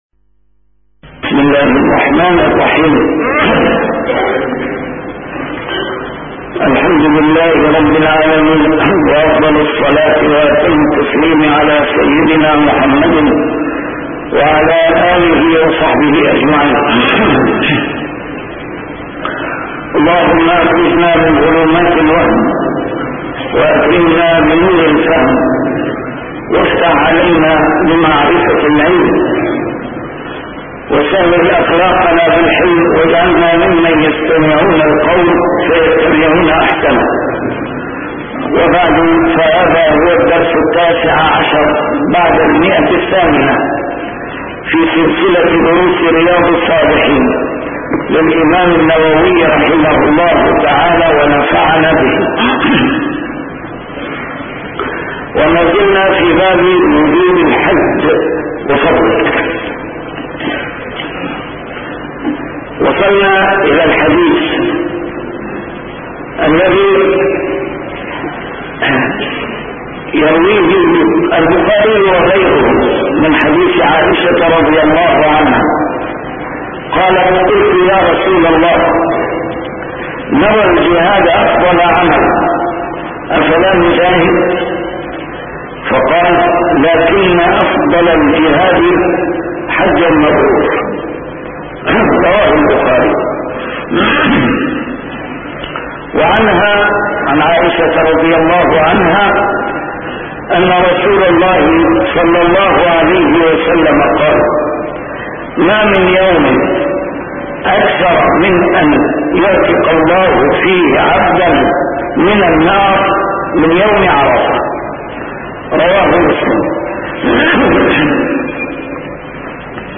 A MARTYR SCHOLAR: IMAM MUHAMMAD SAEED RAMADAN AL-BOUTI - الدروس العلمية - شرح كتاب رياض الصالحين - 819- شرح رياض الصالحين: وجوب الحج